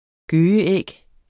Udtale [ ˈgøːjə- ]